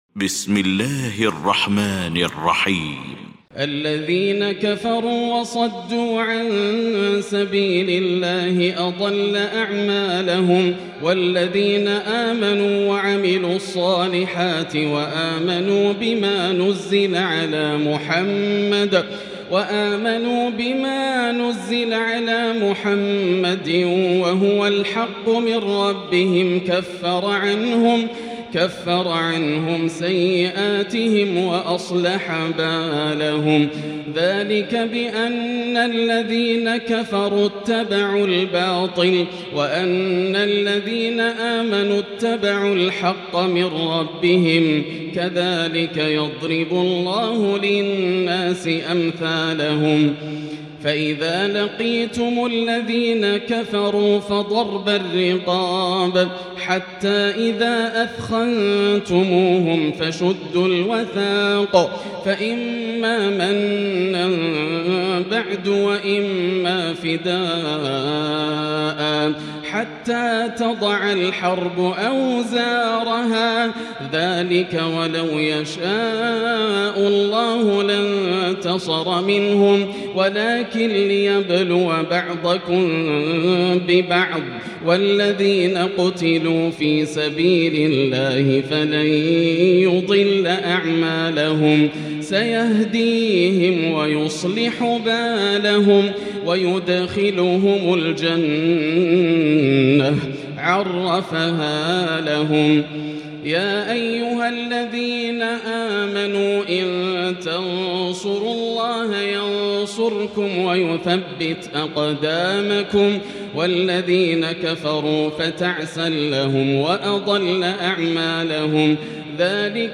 المكان: المسجد الحرام الشيخ: فضيلة الشيخ ياسر الدوسري فضيلة الشيخ ياسر الدوسري محمد